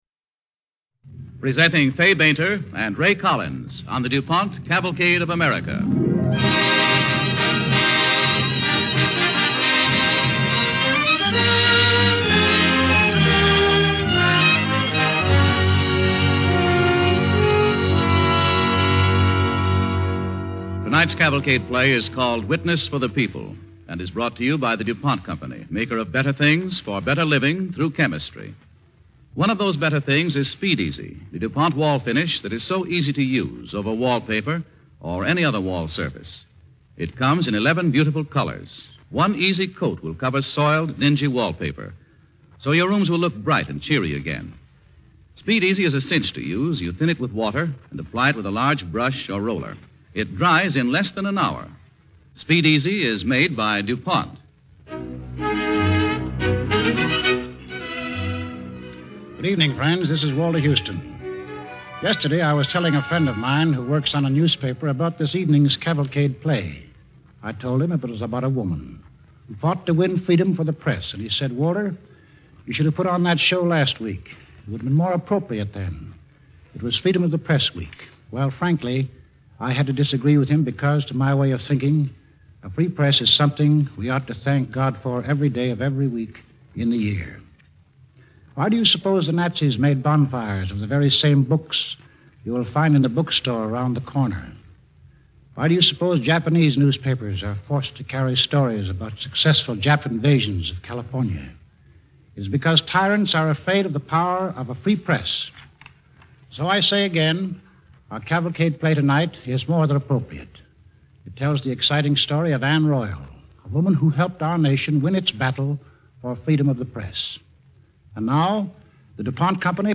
starring Fay Bainter with host Walter Houston